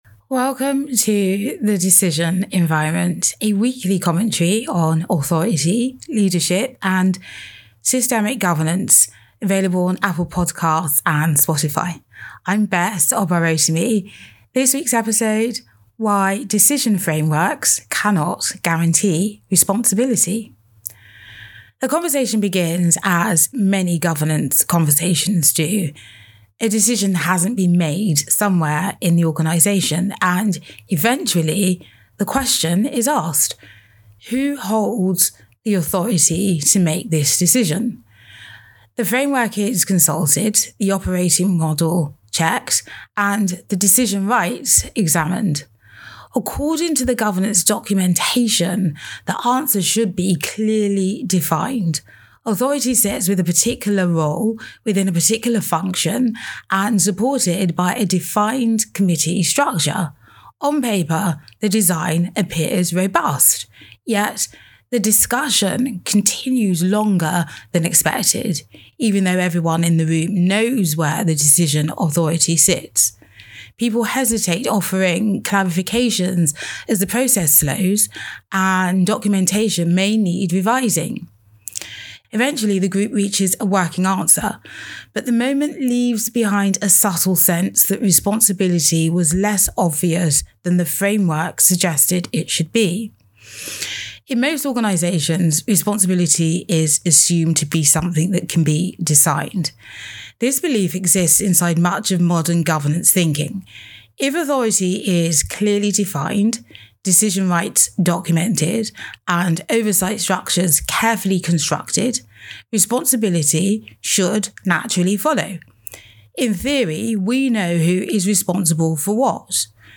decision-frameworks-cannot-guarantee-responsibility-audio-commentary.mp3